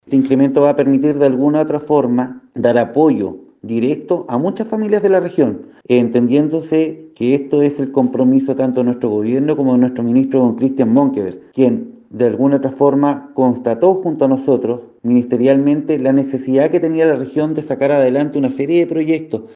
El anuncio fue realizado por autoridades en el sector de Alerce, en Puerto Montt, en plena faena de construcción de 154 viviendas sociales para el comité “Alerce Milenario”.
El seremi subrogante de Vivienda y Urbanismo, Rodrigo Massa, explicó que dicho incremento va a permitir dar apoyo directo a muchas familias de la región.